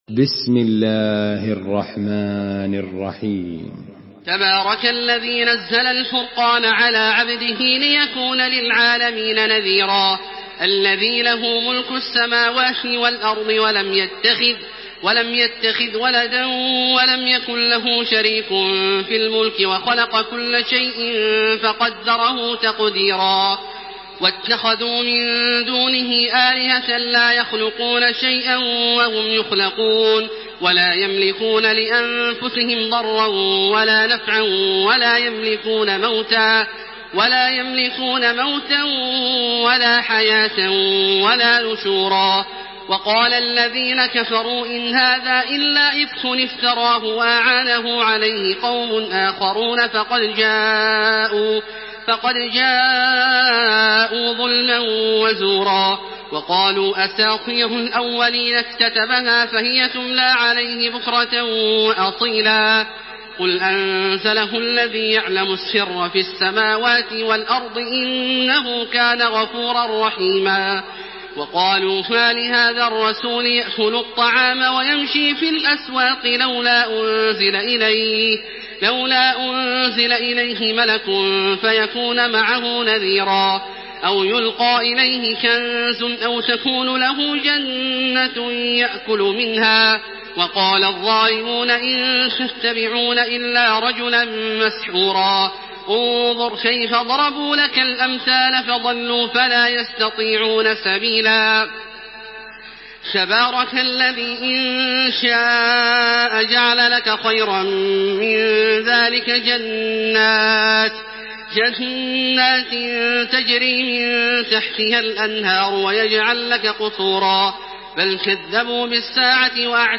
سورة الفرقان MP3 بصوت تراويح الحرم المكي 1428 برواية حفص
مرتل